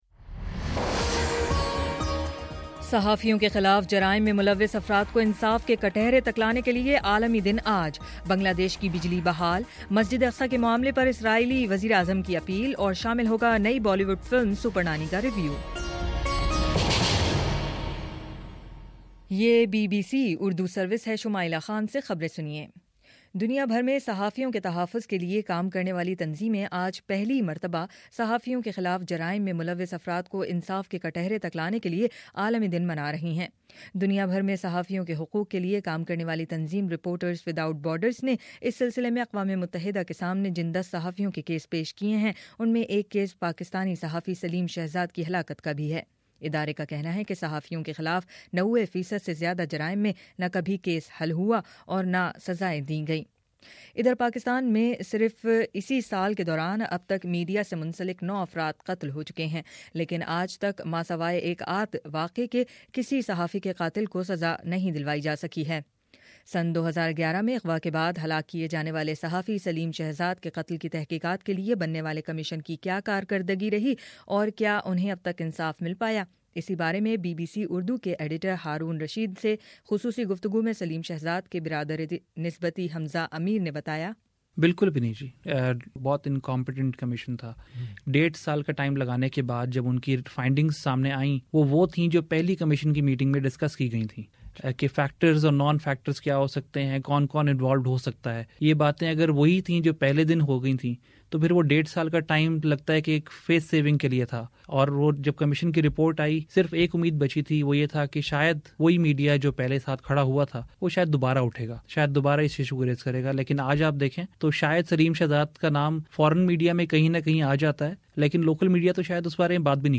دس منٹ کا نیوز بُلیٹن روزانہ پاکستانی وقت کے مطابق صبح 9 بجے، شام 6 بجے اور پھر 7 بجے